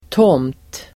Uttal: [tåm:t]